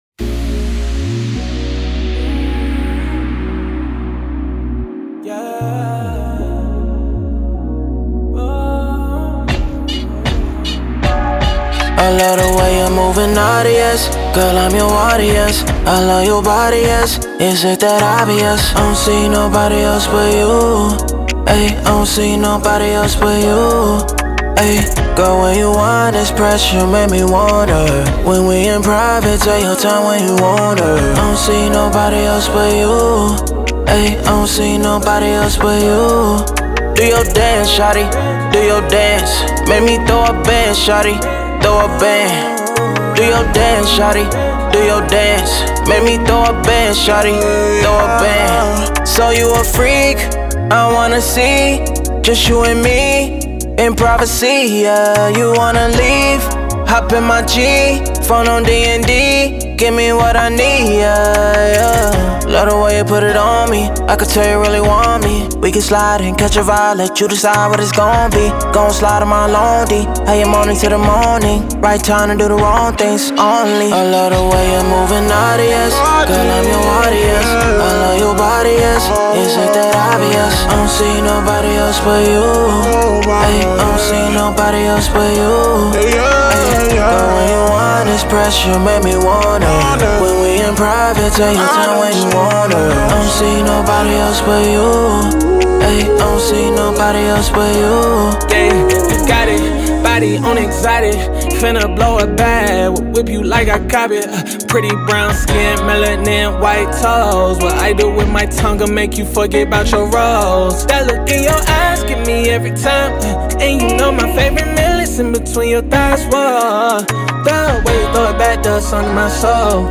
Drill, R&B
Ebm